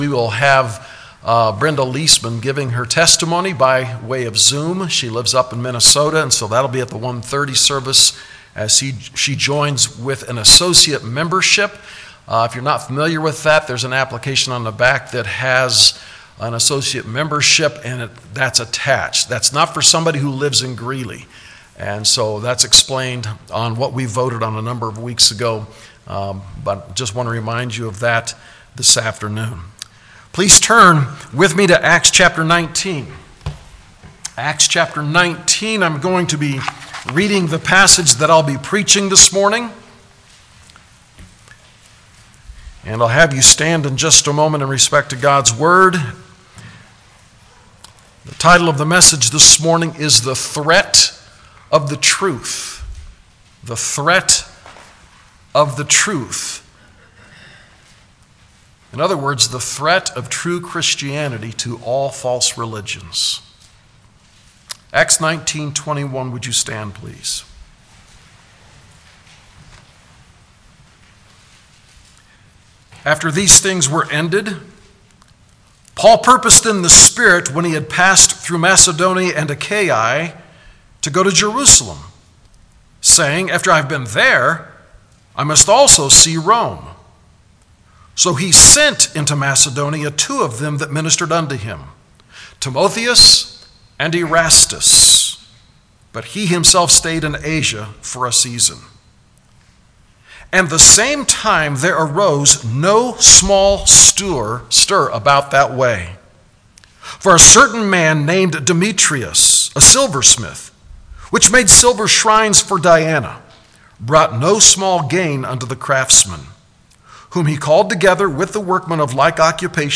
Sermons | Westside Baptist Church - Greeley, CO